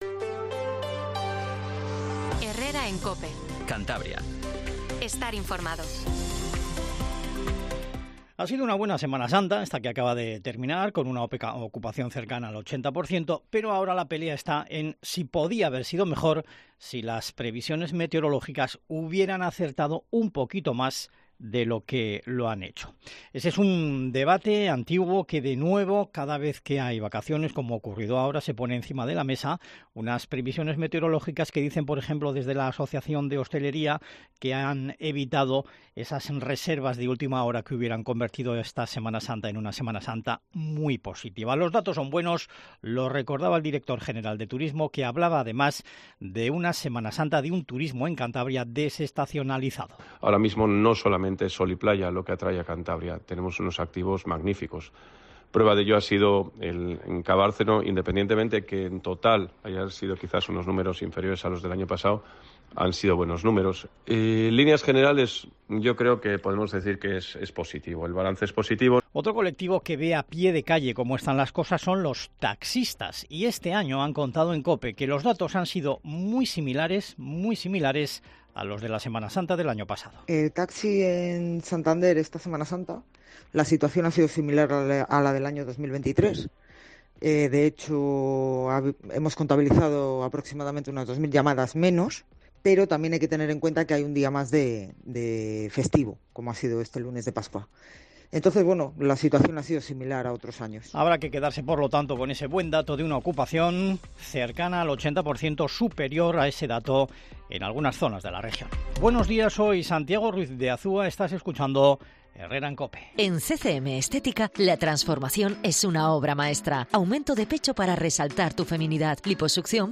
AUDIO: La Linterna con Ángel Expósito se hace en directo desde el Monasterio de Santo Toribio de Liébana para analizar lo que ha sido este Año...